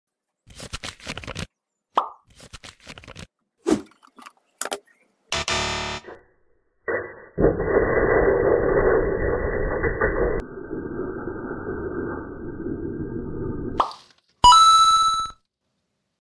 Blending a Balloon! Watch the sound effects free download